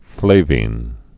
(flāvēn)